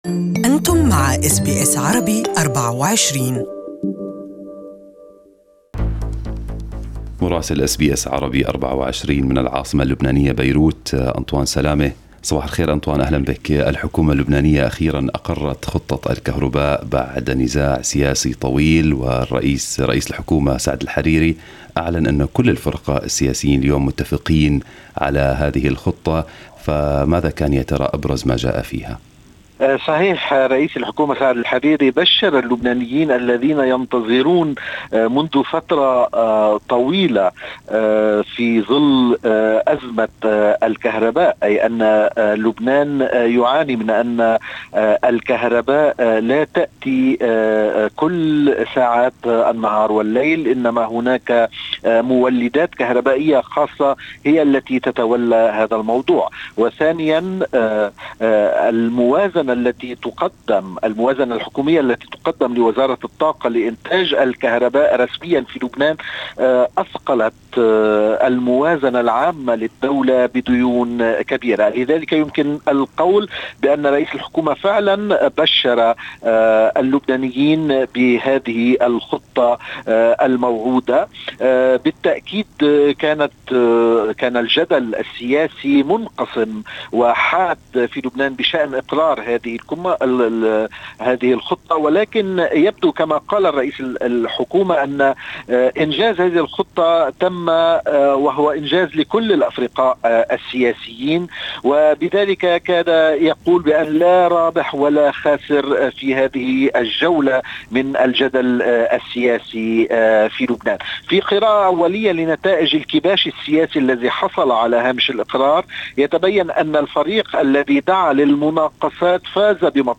Our correspondent in Beirut has the details
Listen to the full report from Beirut in Arabic above